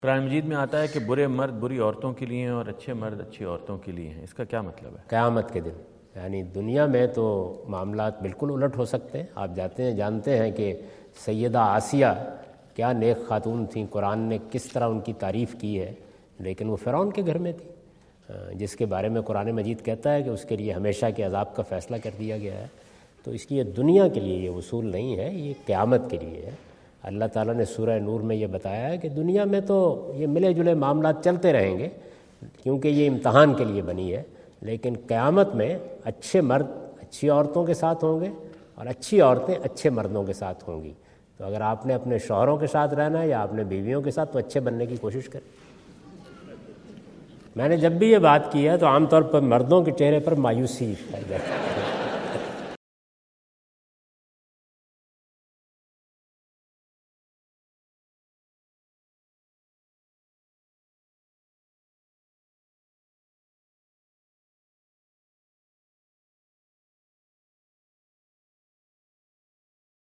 Javed Ahmad Ghamidi answer the question about "Explanation of "Bad Men for Bad Women"" asked at North Brunswick High School, New Jersey on September 29,2017.
جاوید احمد غامدی اپنے دورہ امریکہ 2017 کے دوران نیوجرسی میں ""بُرے مردوں کے لیے بُری عورتیں" کی وضاحت" سے متعلق ایک سوال کا جواب دے رہے ہیں۔